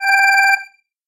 Alarm Tone Short